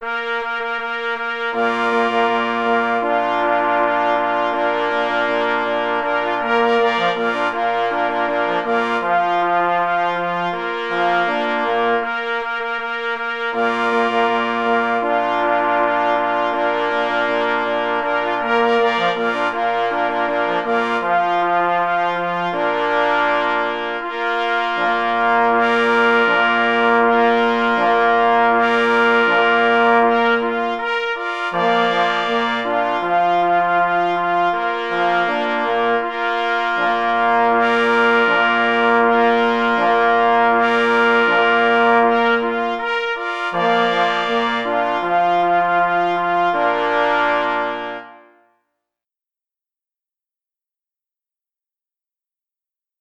W latach 1999 – 2004 skomponował 7 utworów i dokonał pierwszego opracowania polskiej jednogłosowej fanfary nieznanego kompozytora „Na cześć św. Huberta”. Opis zasobu: uroczysta fanfara na 3 plesy i 2 parforsy Tytuł utworu: Na cześć św.
K. Anbild – Na cześć św. Huberta – 3 plesy 2 parforsy | PDF